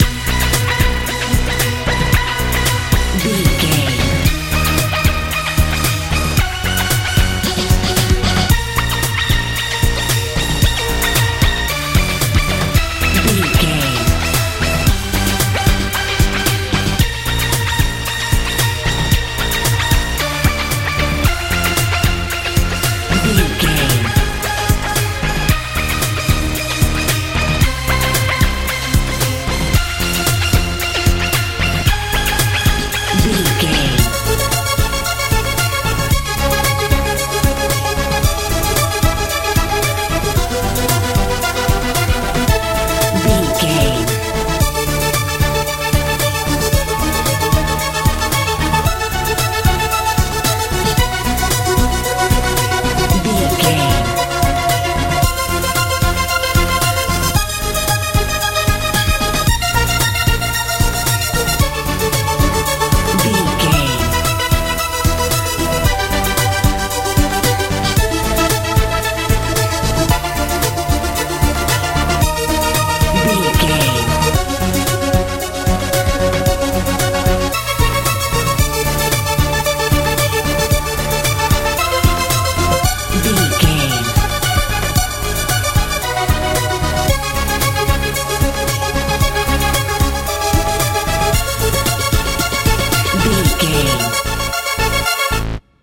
modern dance feel
Ionian/Major
hopeful
joyful
synthesiser
bass guitar
drums
80s
90s
tension
suspense